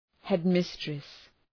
Προφορά
{,hed’mıstrıs}